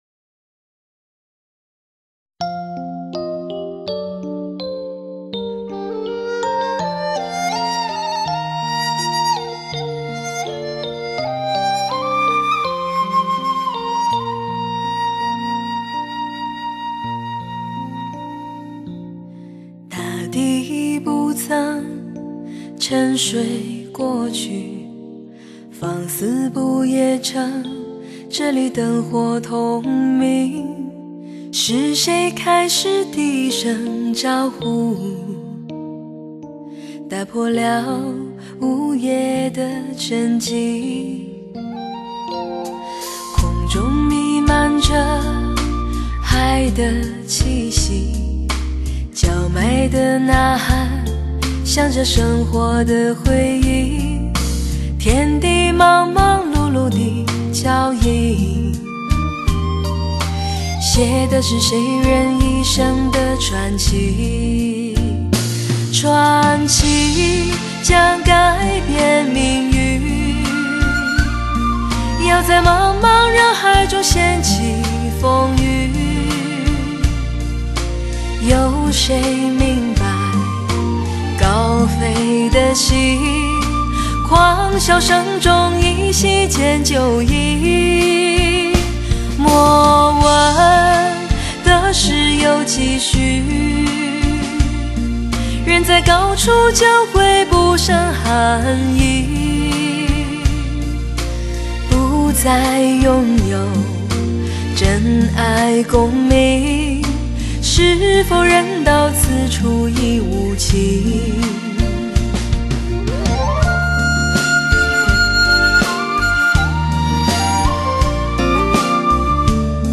真正实现高清，全方位360环绕3D立体音效。